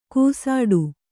♪ kūsāḍu